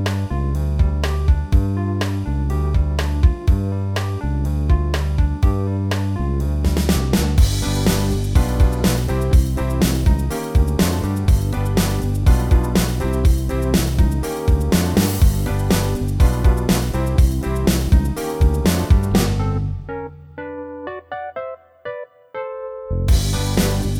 Minus Guitars Pop (1990s) 3:00 Buy £1.50